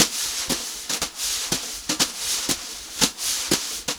120JZBEAT2-L.wav